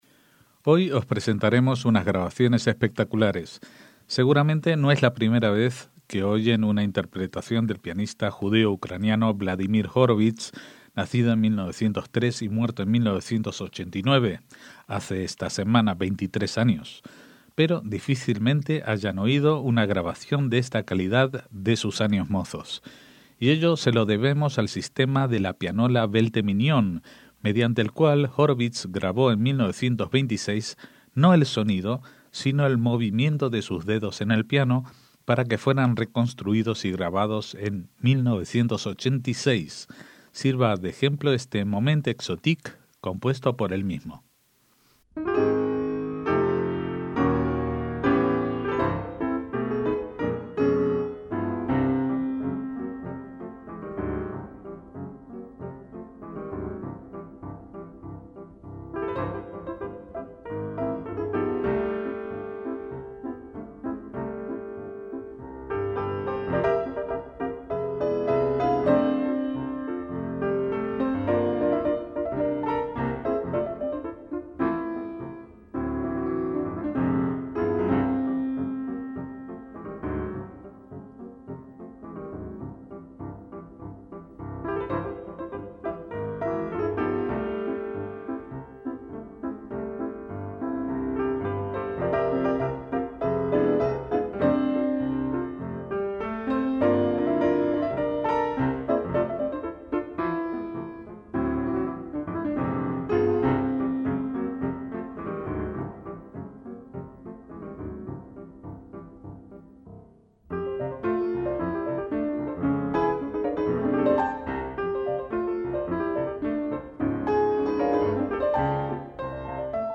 MÚSICA CLÁSICA - El piano mecánico automático Welte-Mignon fue el primer instrumento musical mecánico que hizo posible la reproducción auténtica de piezas musicales para piano, actuando como un piano reproductor.